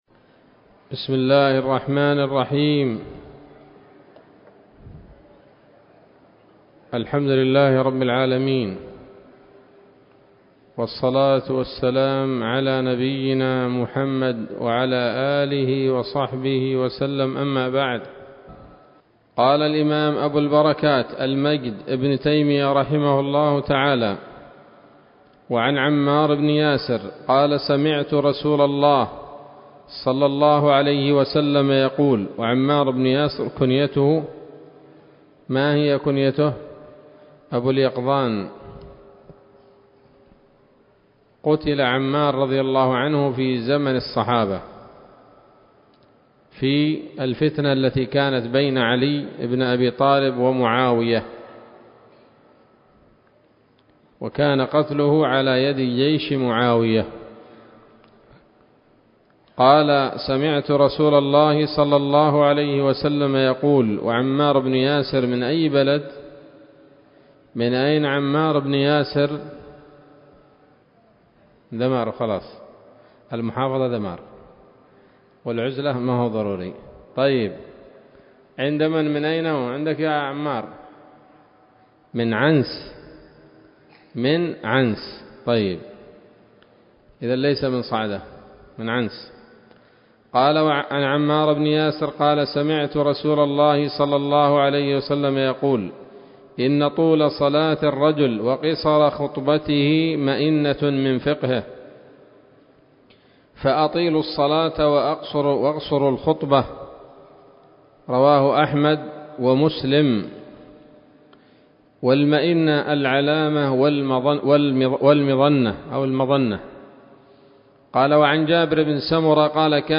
الدرس التاسع والعشرون من ‌‌‌‌أَبْوَاب الجمعة من نيل الأوطار